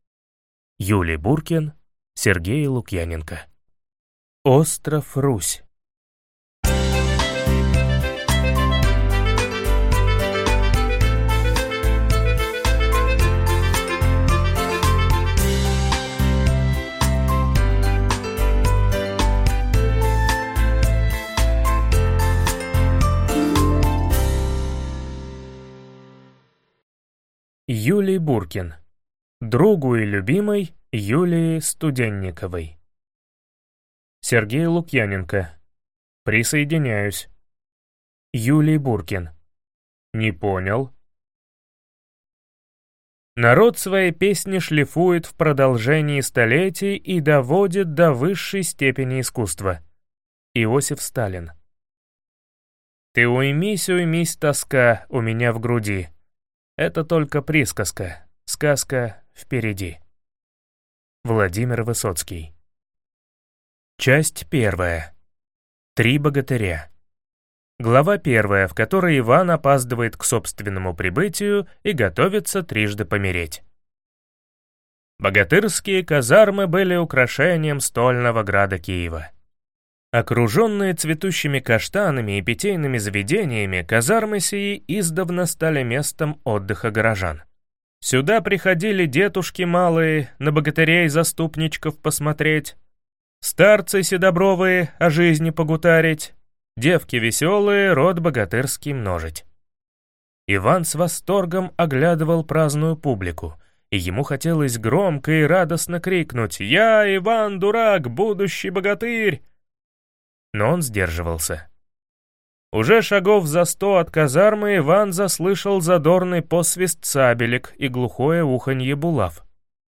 Аудиокнига Остров Русь | Библиотека аудиокниг